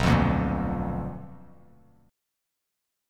BmM7b5 chord